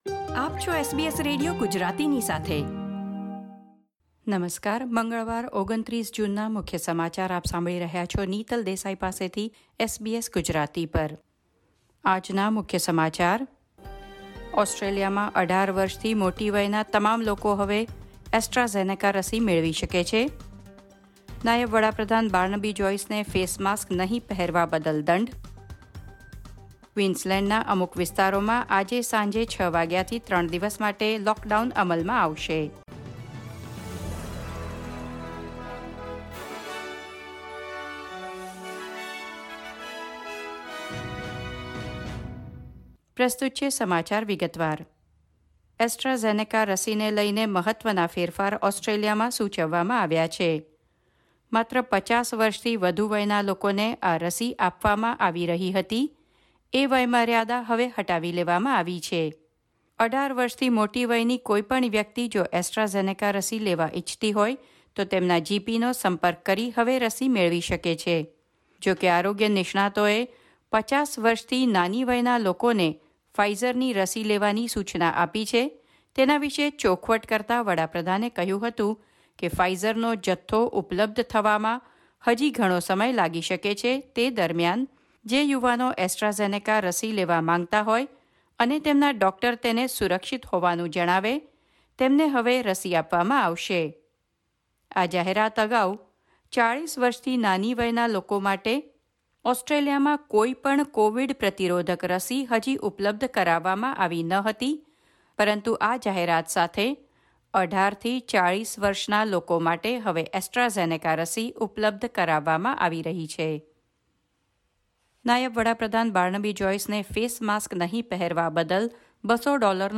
SBS Gujarati News Bulletin 29 June 2021